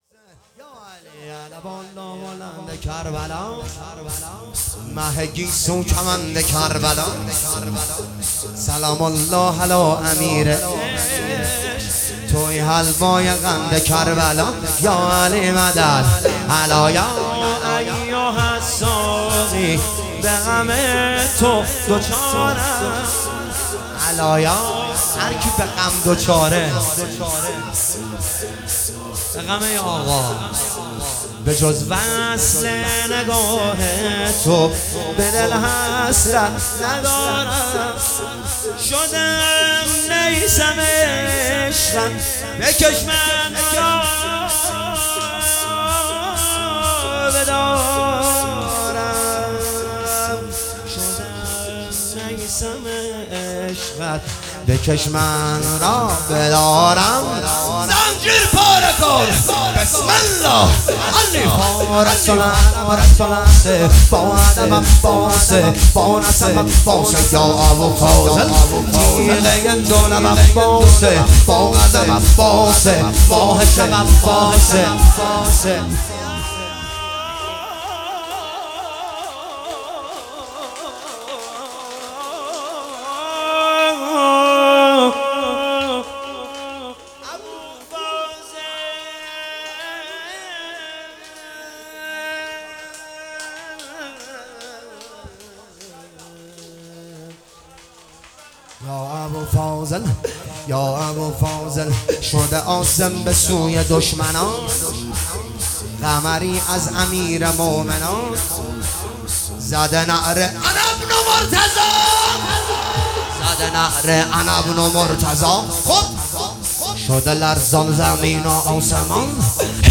جلسه هفتگی اردیبهشت 1404